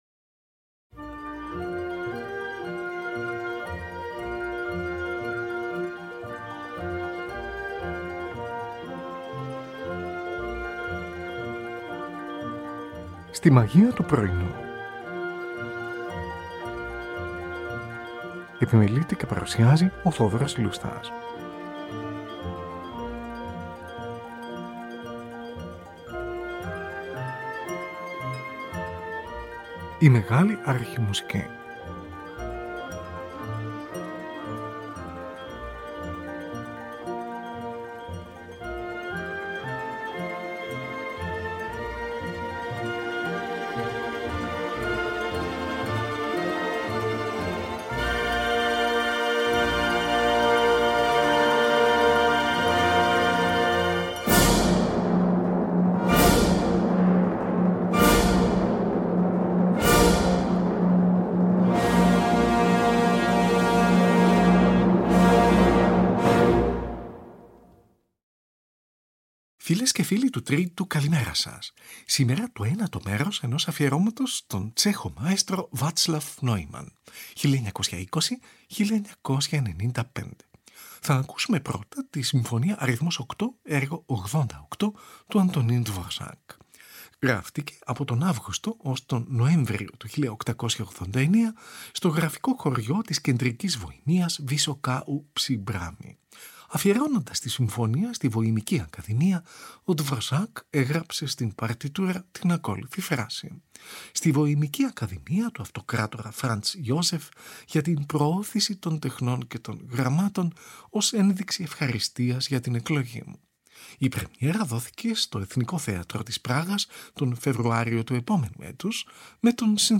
Antonín Dvořák: Humoresque έργο 101 αρ.7, σε ορχηστρική εκδοχή.